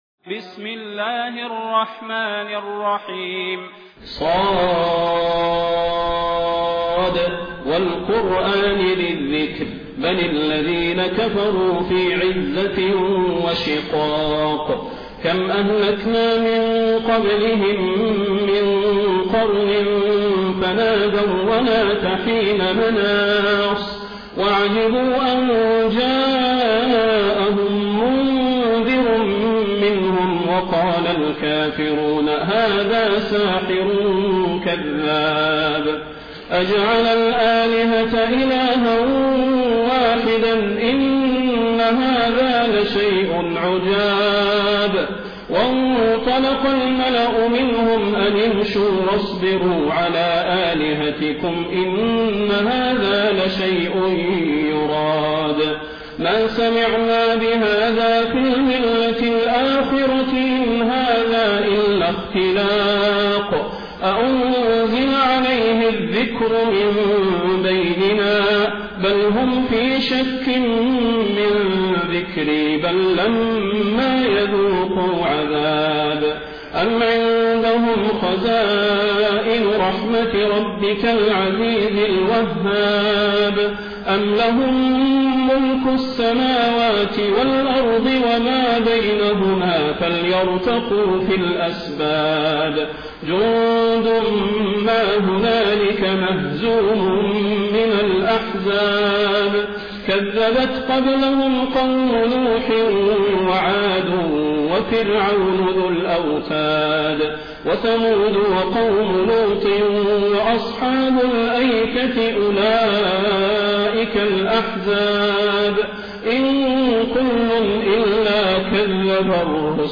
Quran recitations
taraweeh-1433-madina